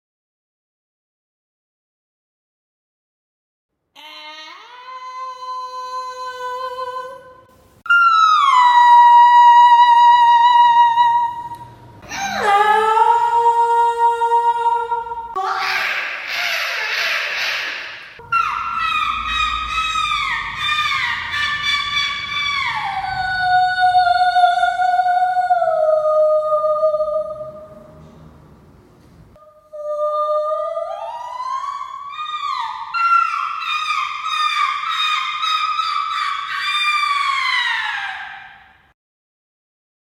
High pitch, trills and warbles…
birdsong-femalesong.mp3